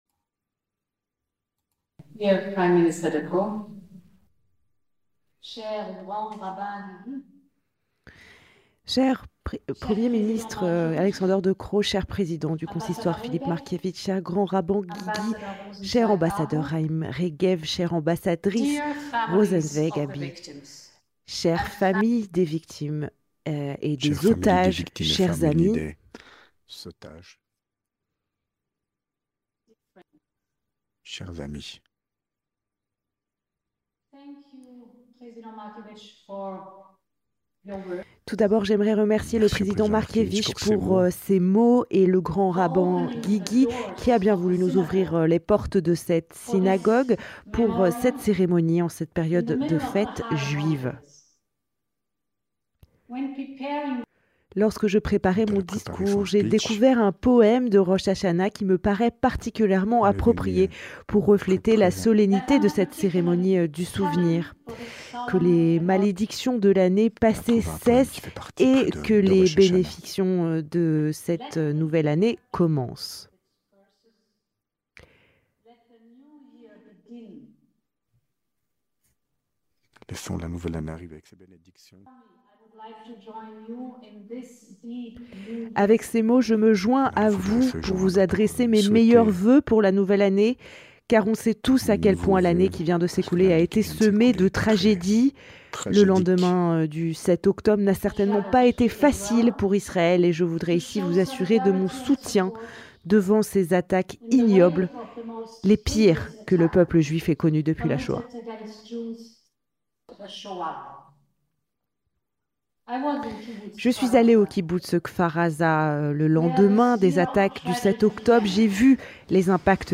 Lors de la cérémonie en hommage aux victimes du 7 octobre et aux otages en la Grande synagogue de l'Europe à Bruxelles, plusieurs personnalités étaient présentes et ont pris la parole. On vous propose de retrouver les discours de 2 intervenantes de marque.
Avec Ursula Von der Leyen, Présidente de la Commission Européenne, et S.E. Idit Rosenzweig-Abu, ambassadrice de l'État d'Israël en Belgique.
Un reportage sur place